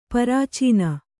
♪ parācīna